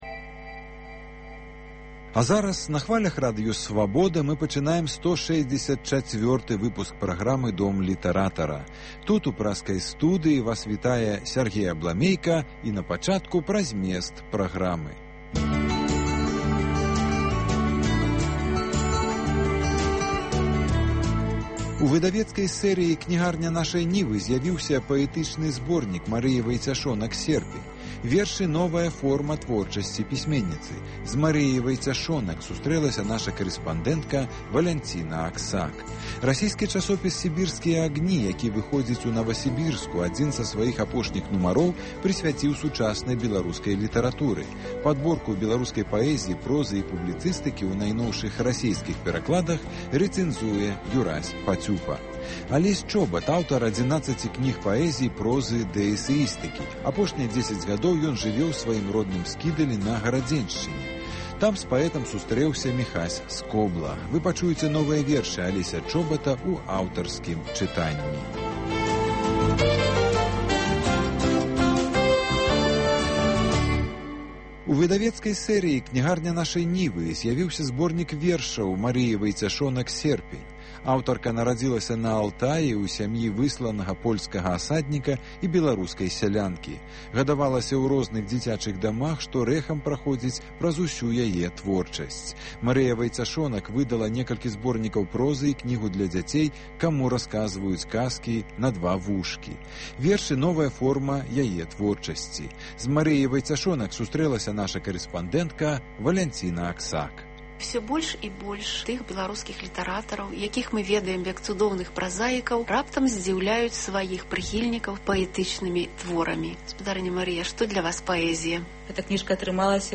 Размова
яго новыя вершы ў аўтарскім чытаньні